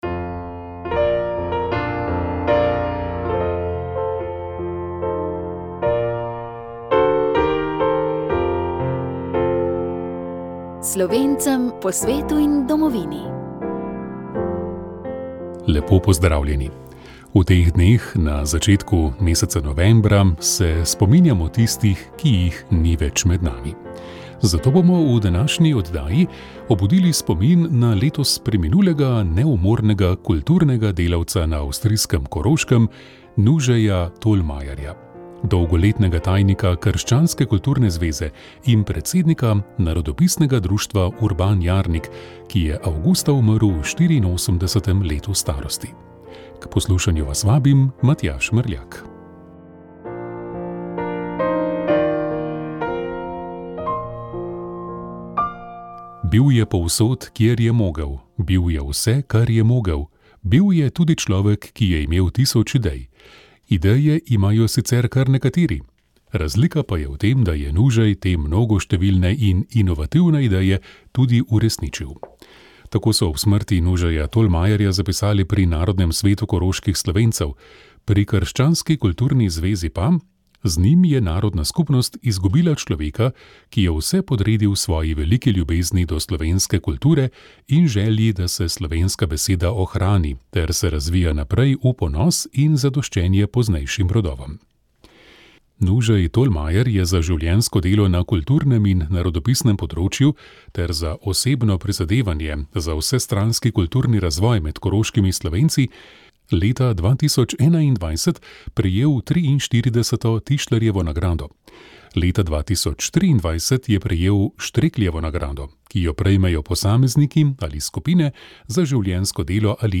Sveta maša
Prenos svete maše iz bazilike Marije Pomagaj na Brezjah dne 2. 5.